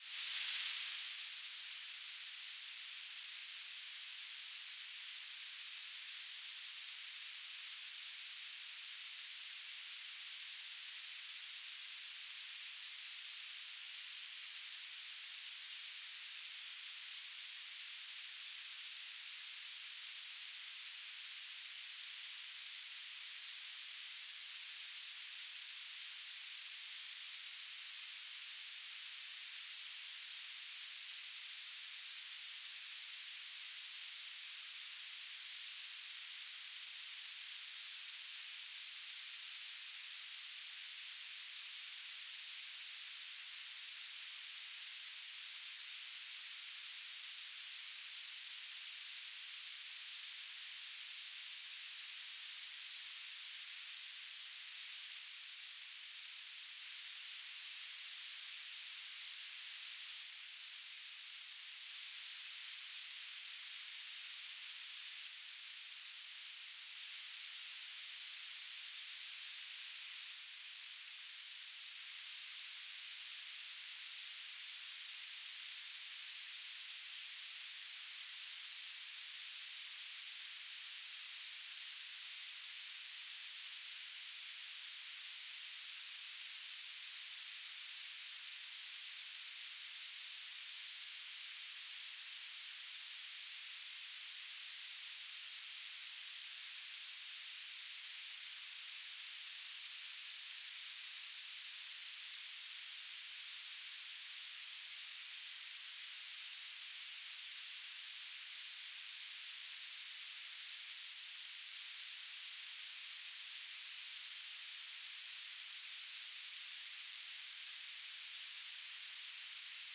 "transmitter_description": "Mode U - BPSK1k2 - Beacon",